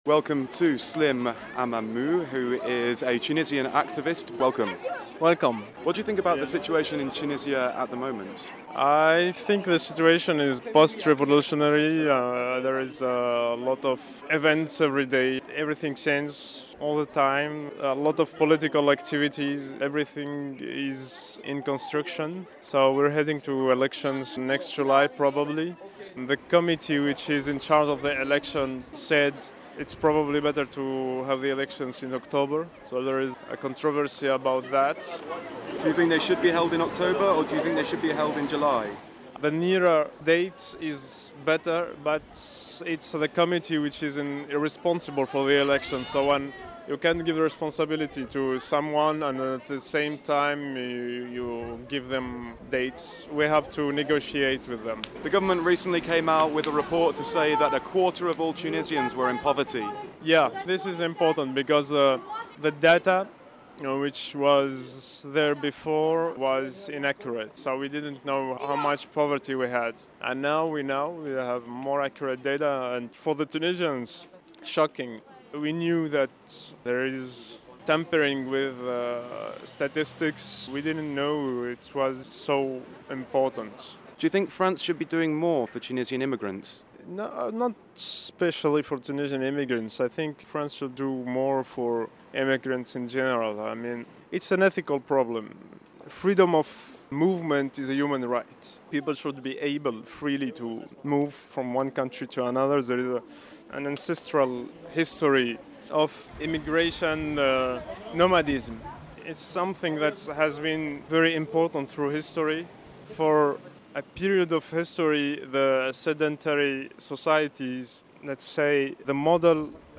Interview: Slim Amamou, Tunisian blogger